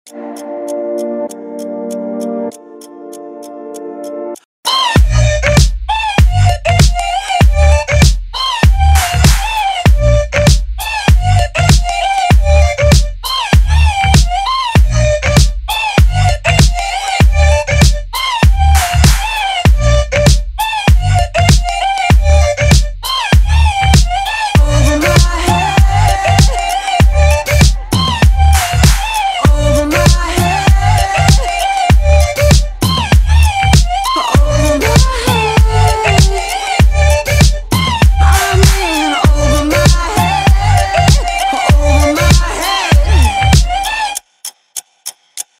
• Качество: 320, Stereo
dance
Electronic
EDM